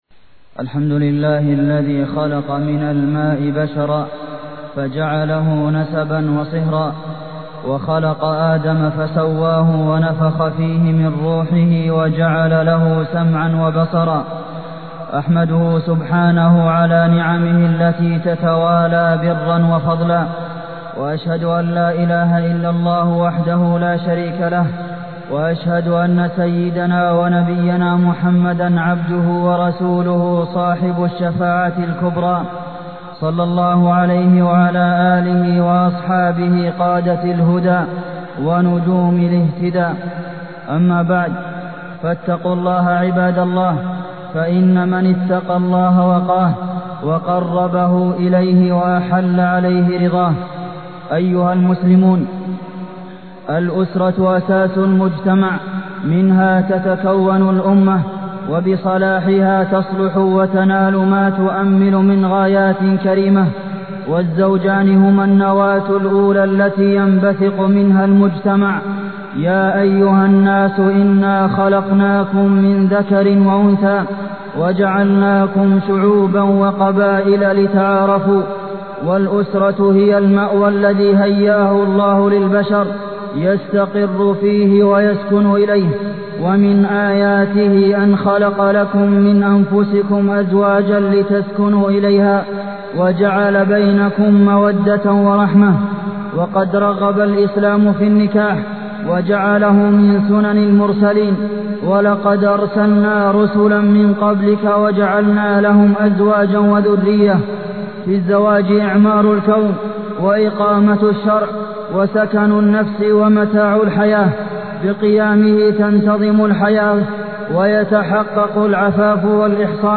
تاريخ النشر ٤ ربيع الأول ١٤٢٠ هـ المكان: المسجد النبوي الشيخ: فضيلة الشيخ د. عبدالمحسن بن محمد القاسم فضيلة الشيخ د. عبدالمحسن بن محمد القاسم الزواج